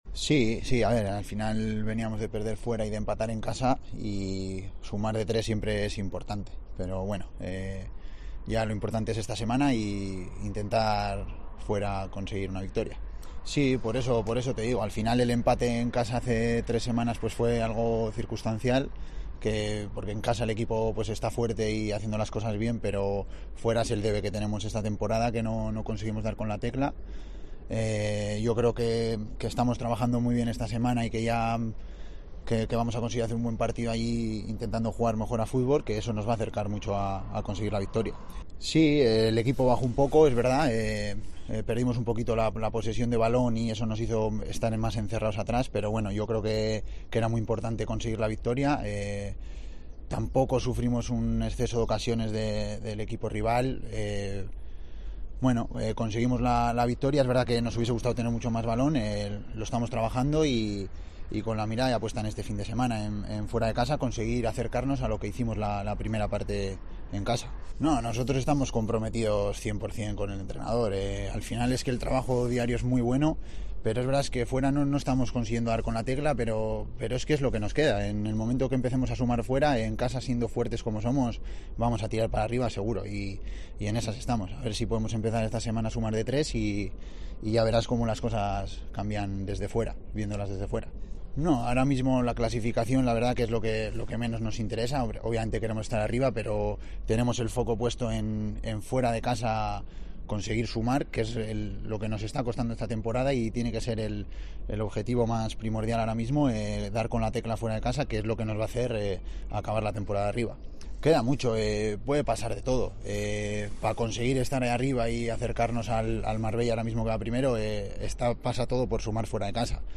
ha atendido a los medios de comunicación en El Mayayo para analizar la actualidad del conjunto universitario tras la victoria (1-0) frente al filial del Cádiz CF en la última jornada de Liga.